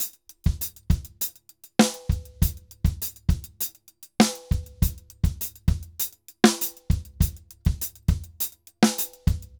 Drums_Salsa 100_2.wav